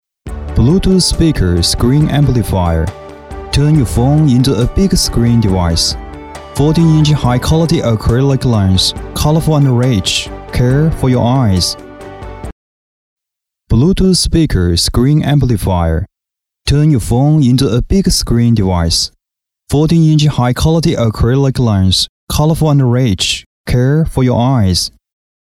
电子产品【科技感】